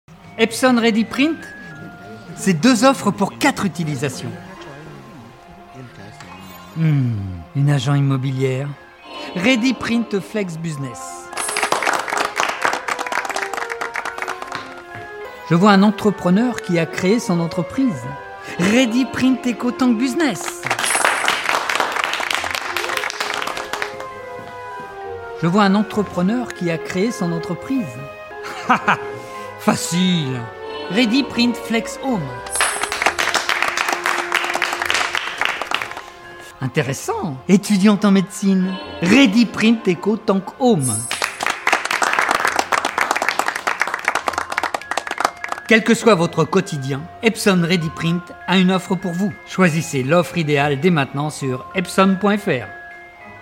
Voix Off - Publicité Epson
36 - 60 ans - Ténor